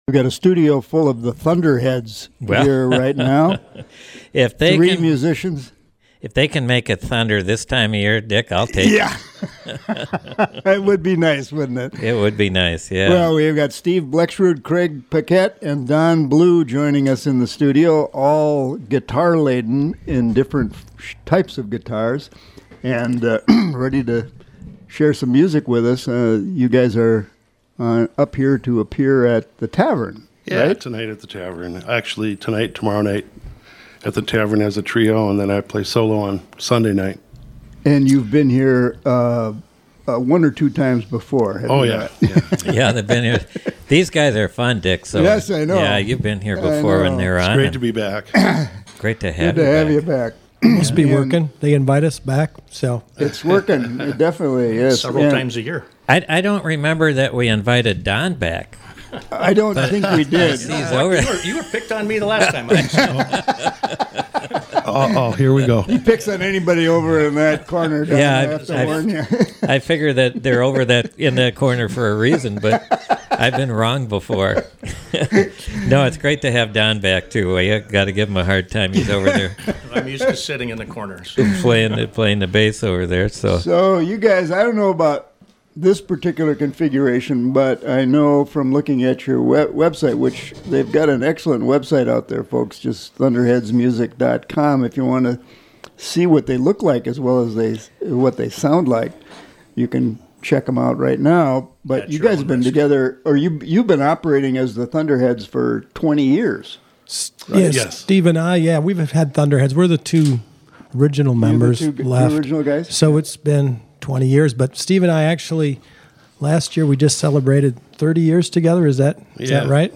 Twin Cities-based band
Listen for fun conversation and great music.
Live Music Archive